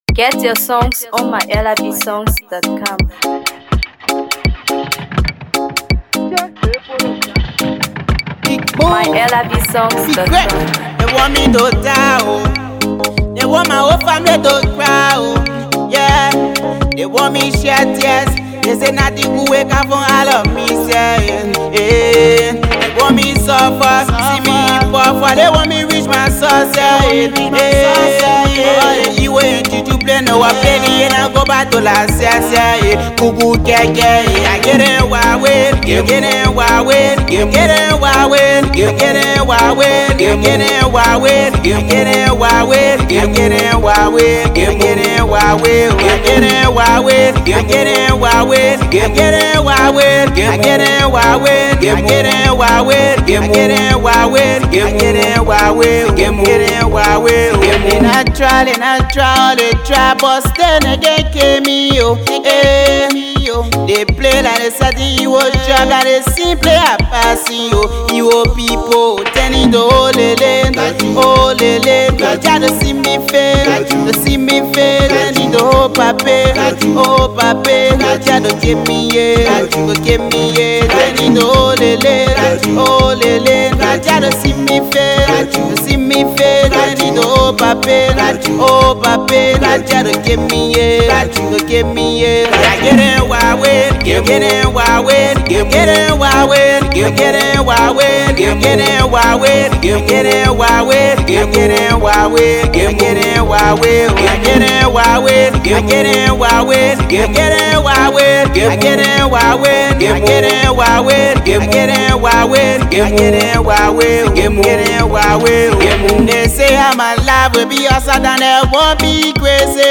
Afro Pop
With his raw delivery and heartfelt storytelling